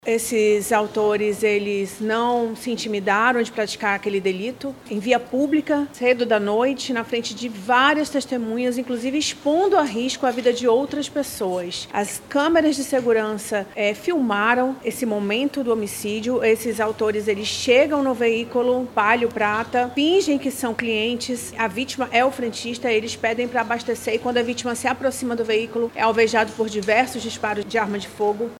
Em coletiva de imprensa realizada nesta quarta-feira (19)